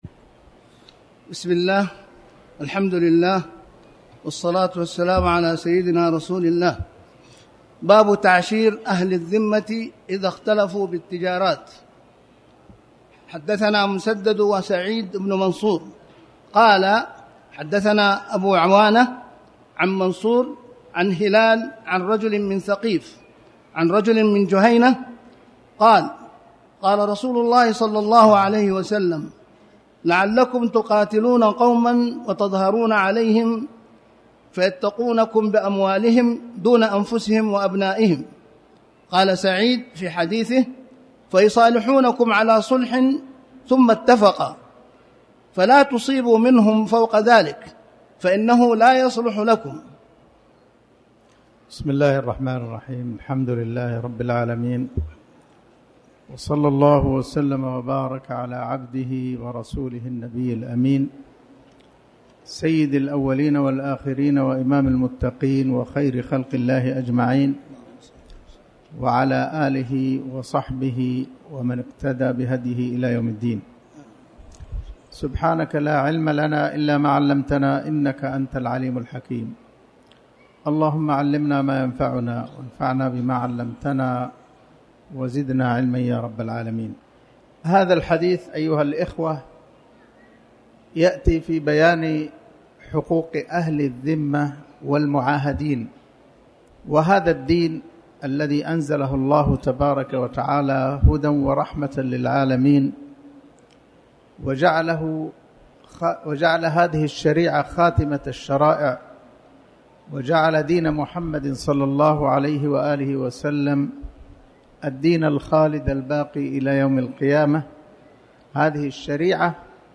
تاريخ النشر ٢٧ شوال ١٤٣٩ هـ المكان: المسجد الحرام الشيخ